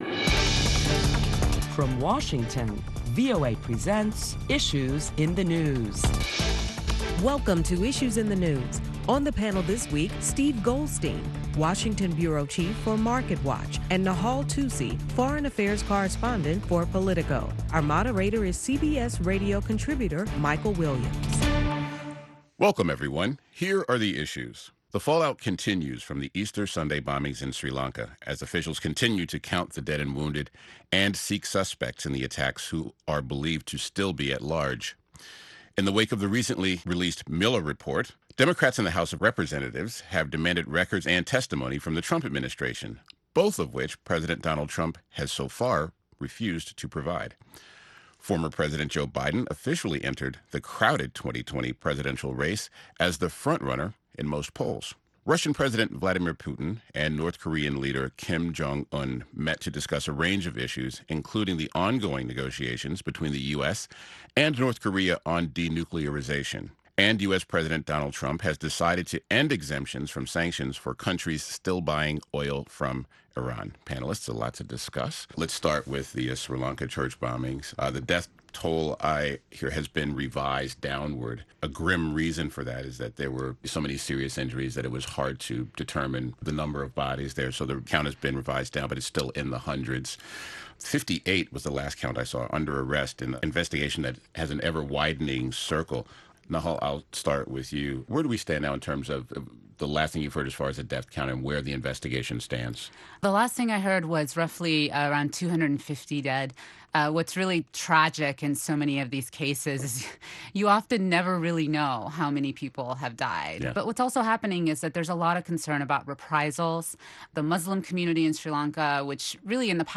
Listen to a panel of prominent Washington journalists dialogue in the week's headlines including the Sri Lanka church bombings, Trump’s defiance of Congress, and the U.S. declines to renew waivers to five countries who import oil from Iran.